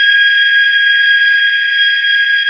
rr3-assets/files/.depot/audio/sfx/forced_induction/spool_03.wav
spool_03.wav